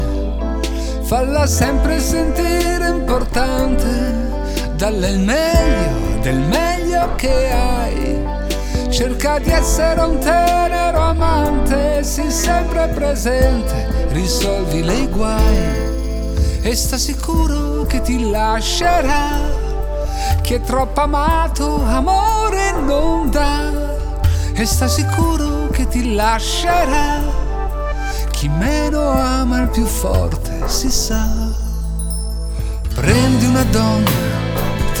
Жанр: Поп музыка